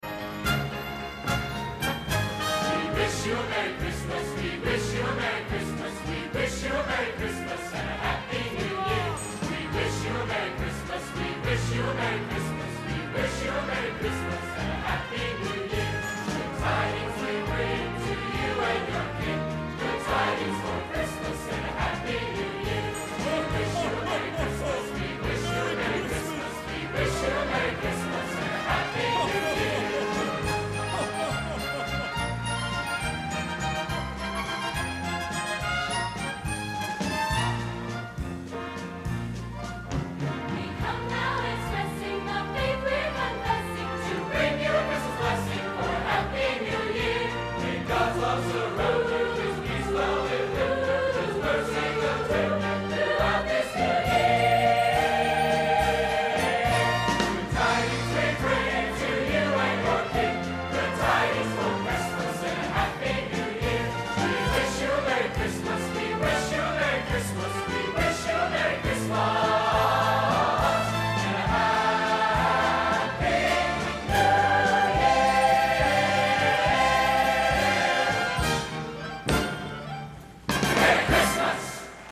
Genre: Traditional Holiday | Type: Christmas Show |